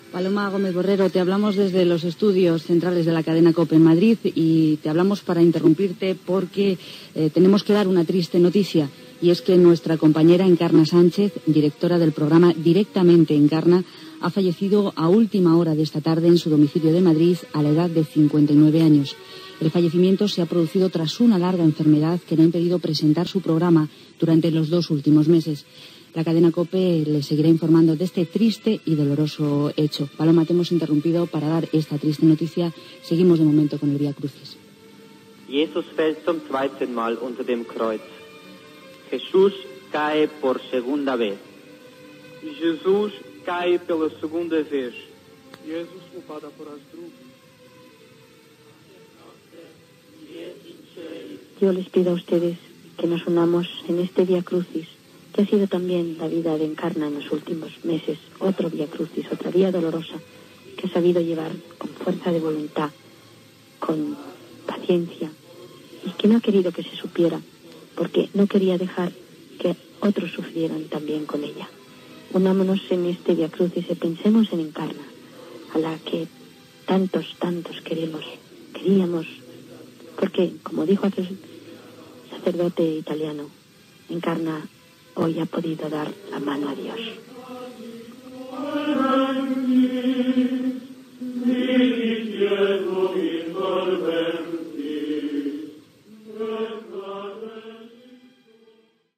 Comunicat de la mort de la locutora i periodista Encarna Sánchez i transmissió del via crucis des de Roma